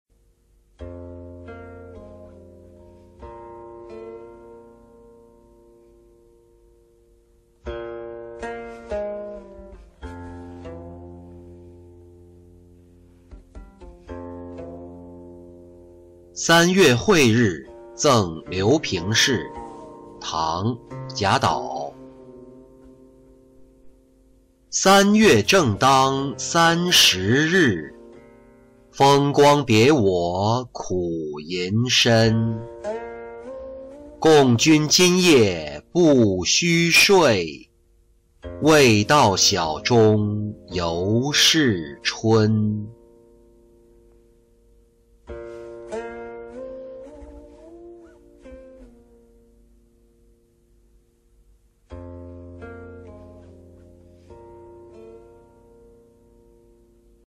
三月晦日赠刘评事-音频朗读